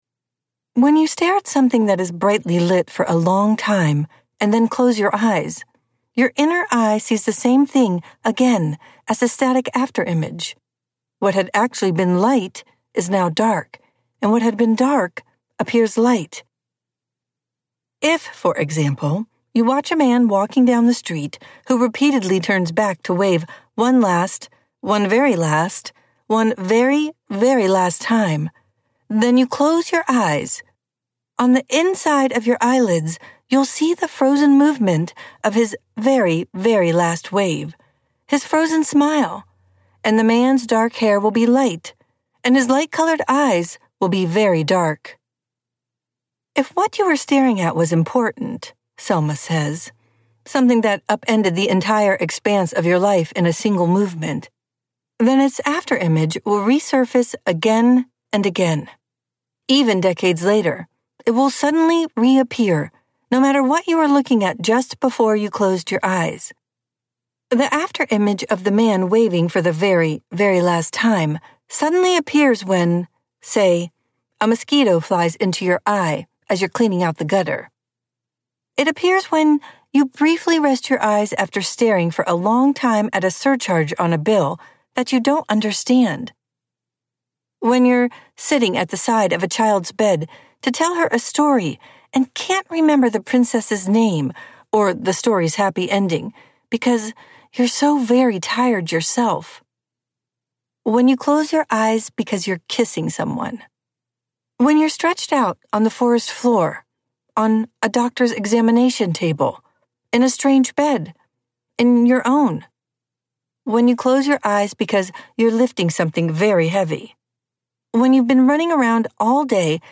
What You Can See from Here - Vibrance Press Audiobooks - Vibrance Press Audiobooks